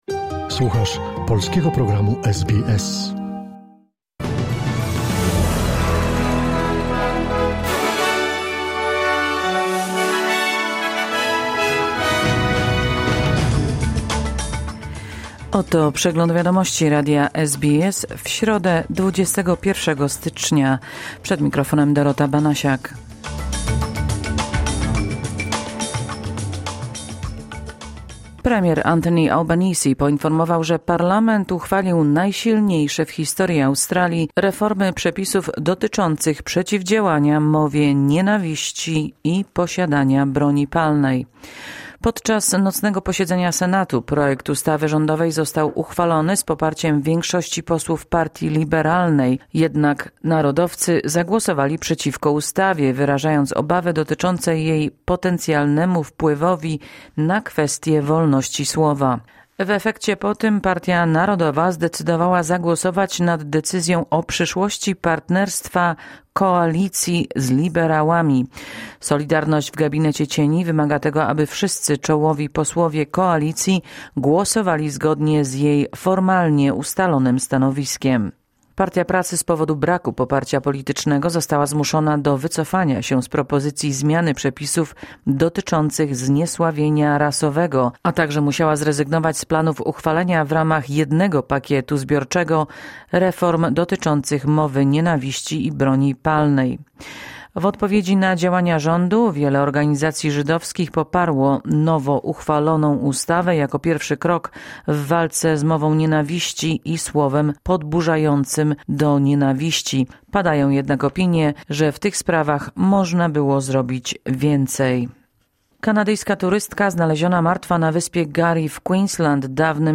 Skrót najważniejszych doniesień z Australii i ze świata, w opracowaniu polskiej redakcji SBS.